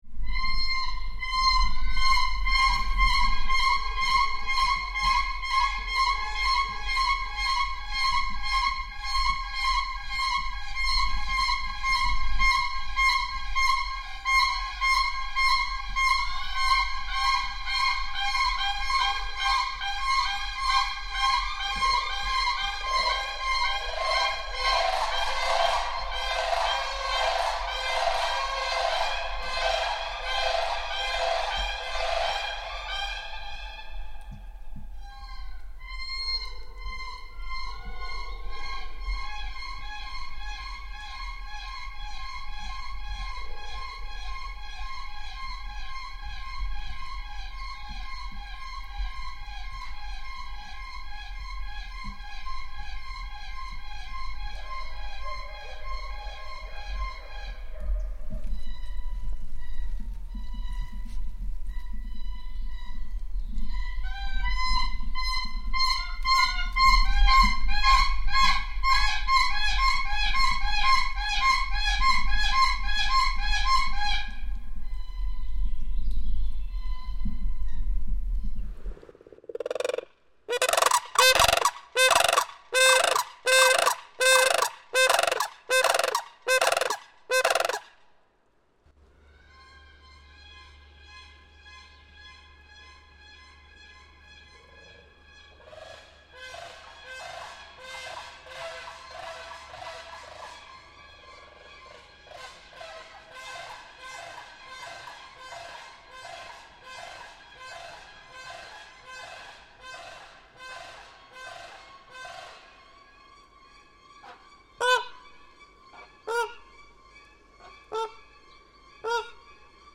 Cranes choir
This is a recording of different species (grey, white, black, American, Japanese) of cranes, April 2015. Birds are sitting in aviaries.